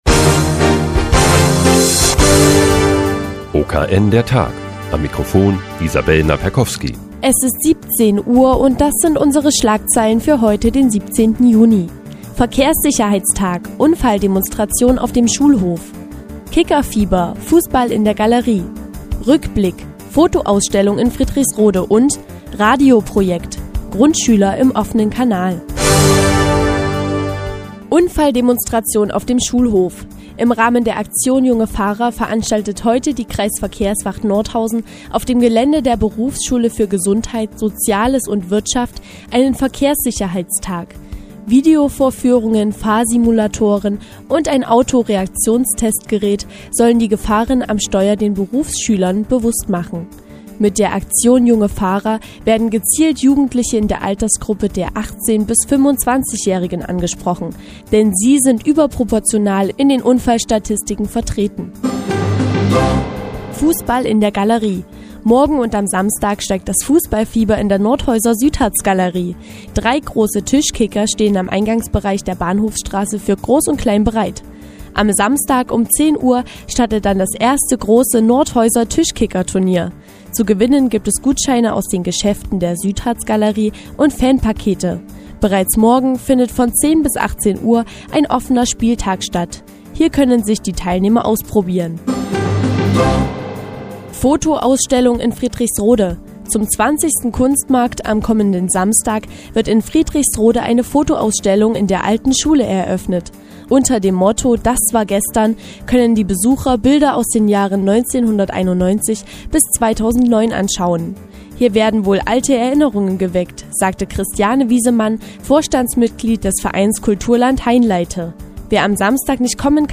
Die tägliche Nachrichtensendung des OKN ist nun auch in der nnz zu hören. Heute geht es um eine Unfalldemonstration für junge Fahrer und die neue Fotoausstellung in Friedrichsrode.